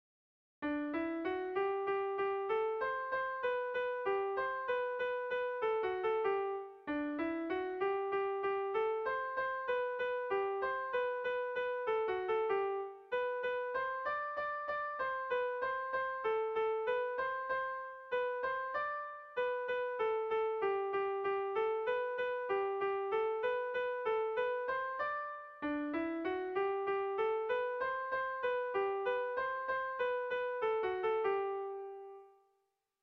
Air de bertsos - Voir fiche   Pour savoir plus sur cette section
Sentimenduzkoa
AABDA